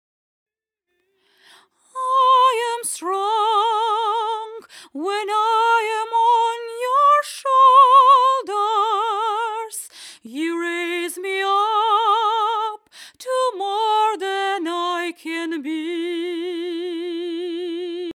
Vocal Original
vocal_original.mp3